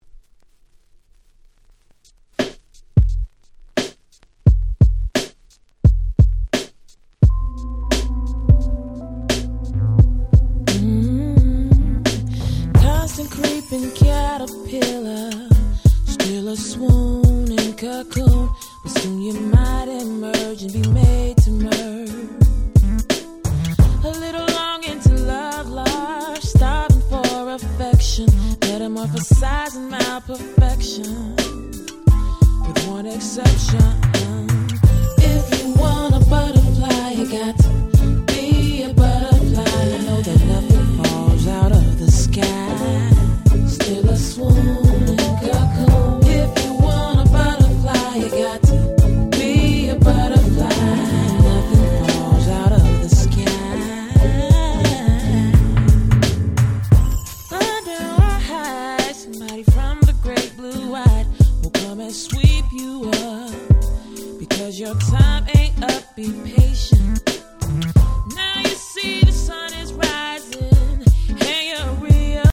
01' Smash Hit R&B / Neo Soul !!
ネオソウル